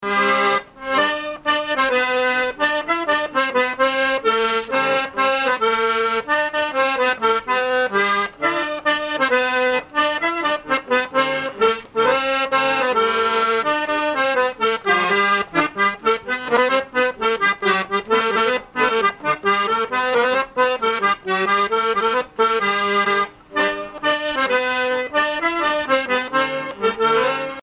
Chants brefs - A danser
musique à danser à l'accordéon diatonique
Pièce musicale inédite